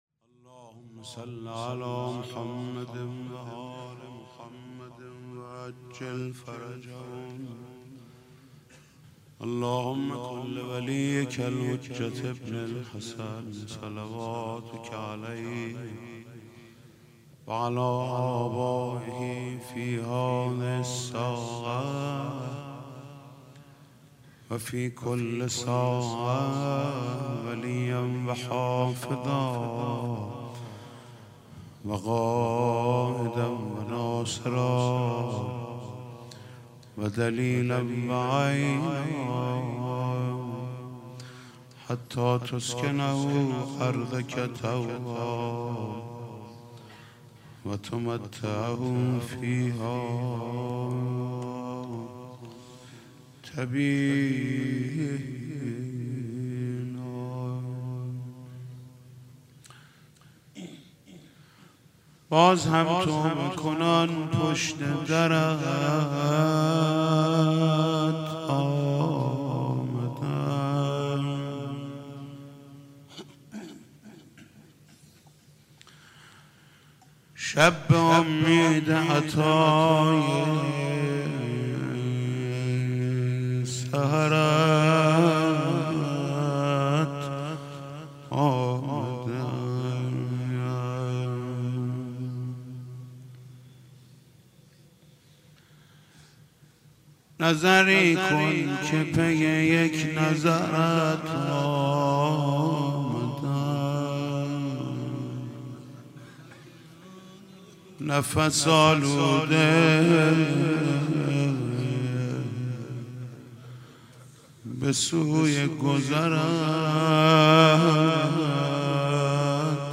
مراسم شب هجدهم ماه مبارک رمضان با مداحی حاج محمود کریمی در امام زاده علی اکبر چیذر برگزار گردید